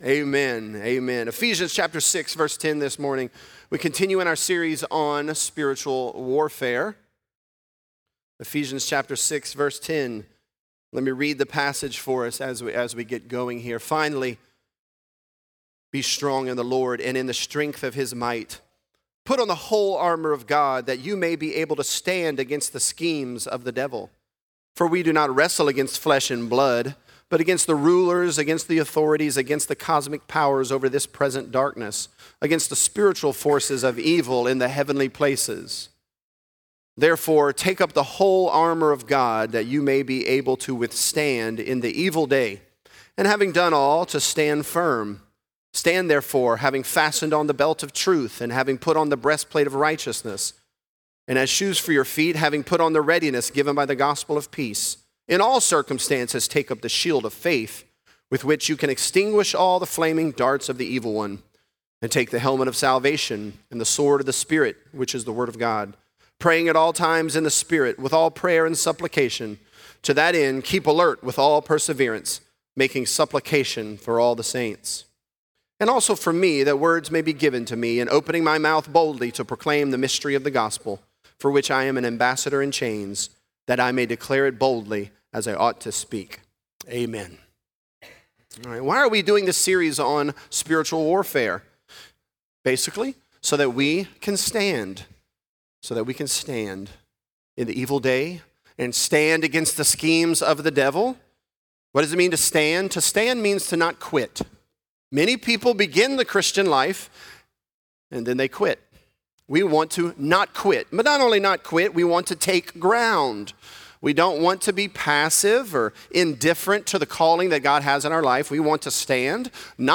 The Armor Of God: The Shield Of Faith | Lafayette - Sermon (Ephesians 6)